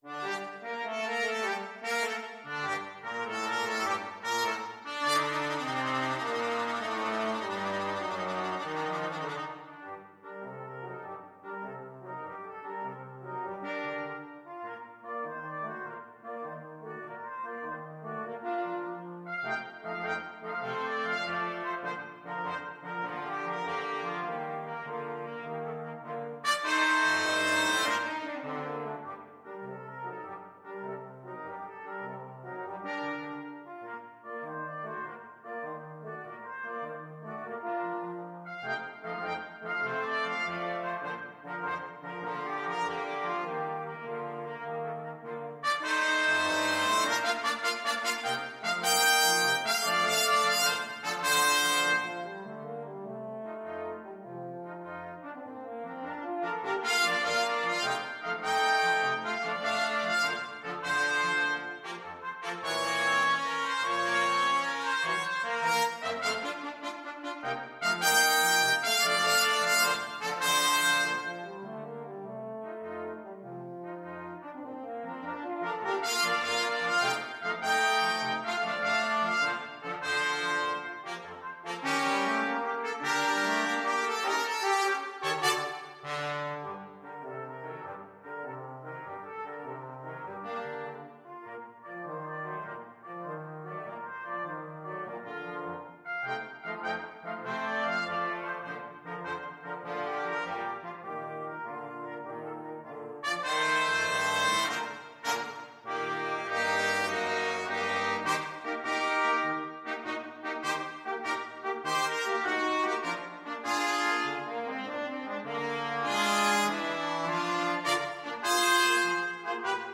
Trumpet 1Trumpet 2French HornTrombone
6/8 (View more 6/8 Music)
Allegro moderato . = c.100 (View more music marked Allegro)
Brass Quartet  (View more Intermediate Brass Quartet Music)
Pop (View more Pop Brass Quartet Music)